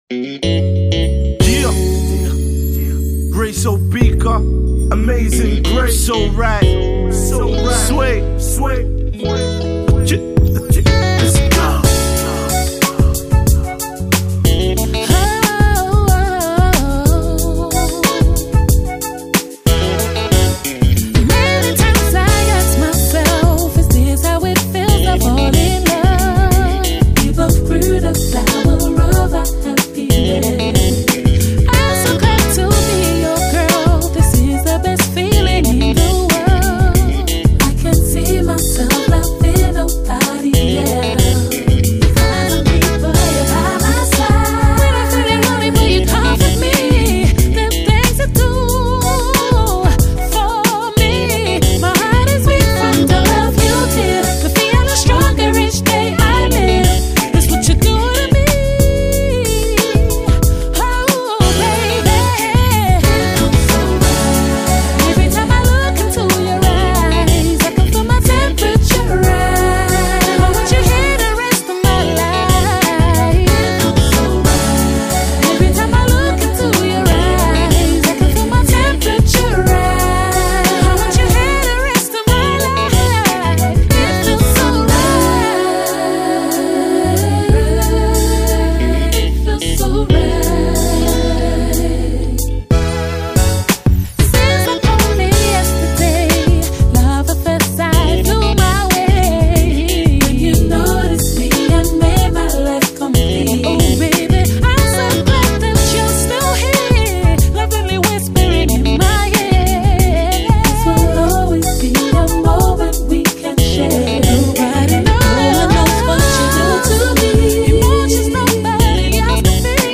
London R&B Singer
This London R&B Singer is an Indie-Soul singer/songwriter was born in the UK and is of Nigerian heritage as both of her parents were born there.
London-RB-Singer-So-Right.mp3